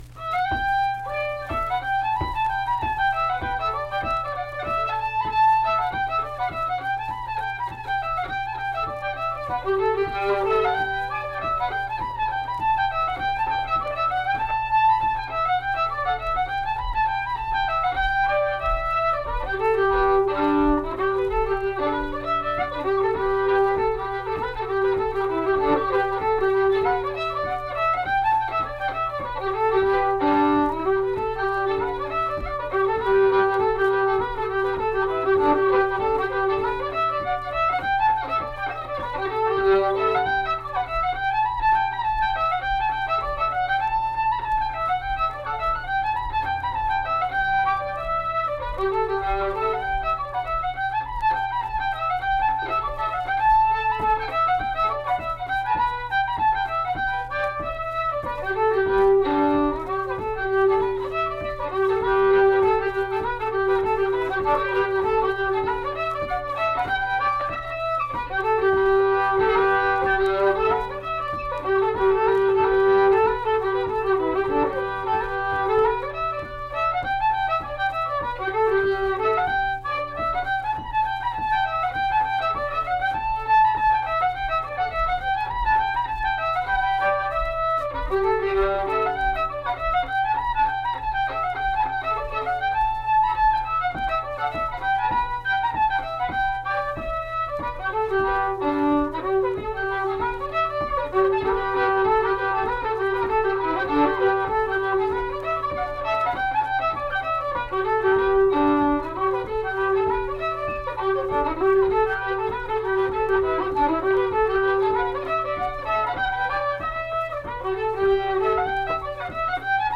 Tug Boat - West Virginia Folk Music | WVU Libraries
Unaccompanied fiddle music
Instrumental Music
Fiddle